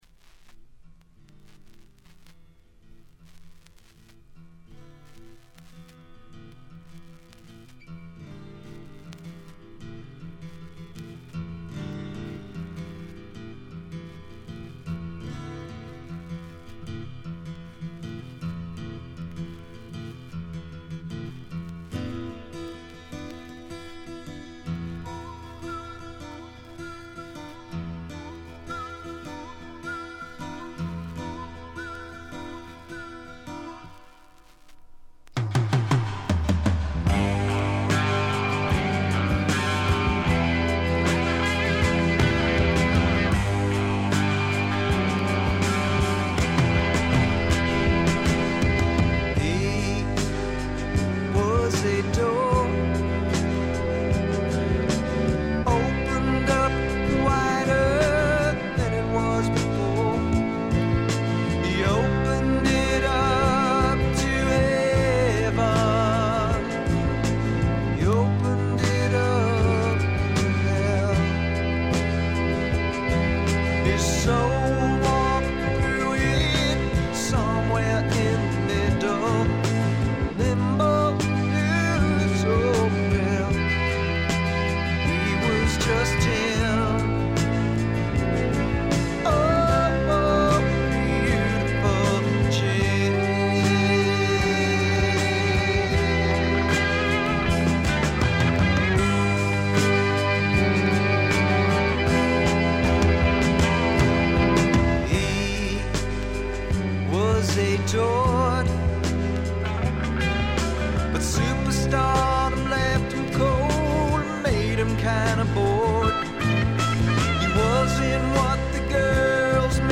見た目に反して、全体にバックグラウンドノイズ、チリプチが多め。
基本は英国的としか言いようのない重厚で深い陰影のある哀愁のフォークロックです。
試聴曲は現品からの取り込み音源です。